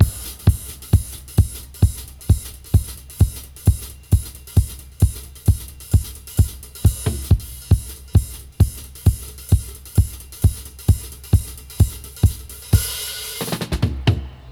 134-FX-01.wav